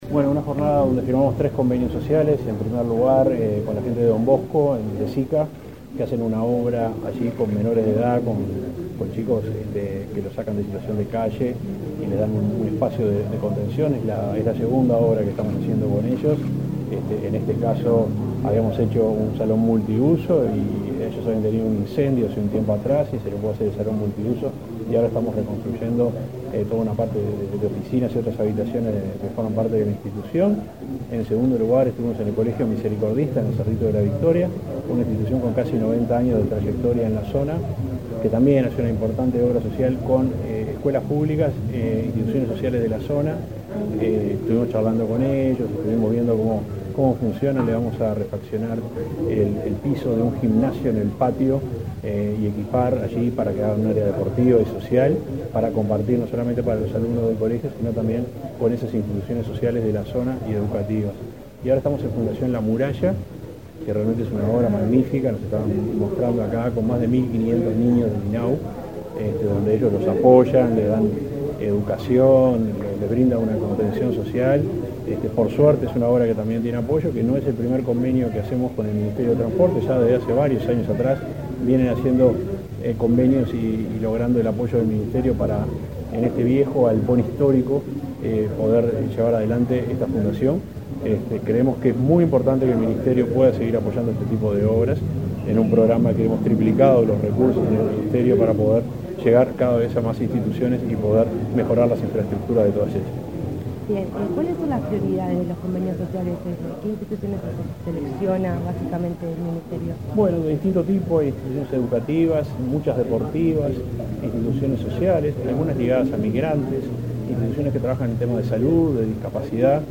Entrevista al subsecretario de Transporte y Obras Públicas, Juan José Olaizola
Tras el evento, el jerarca realizó declaraciones a Comunicación Presidencial.